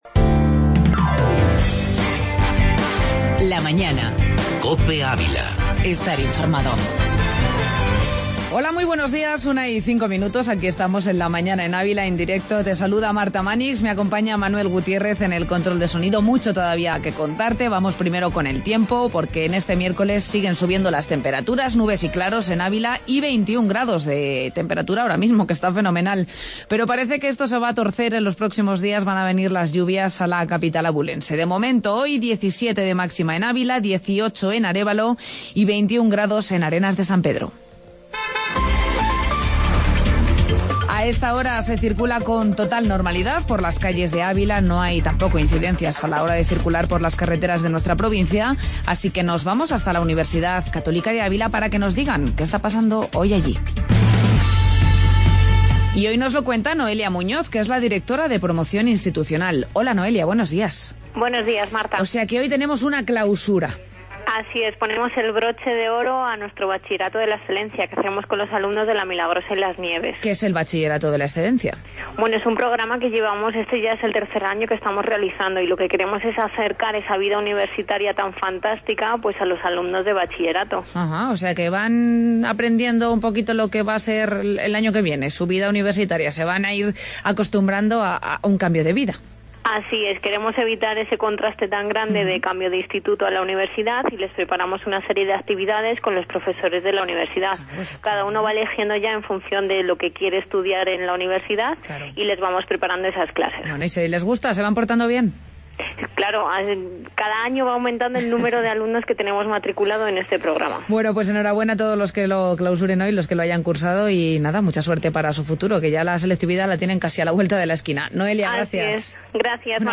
AUDIO: Garbántel y Tertulía Deportiva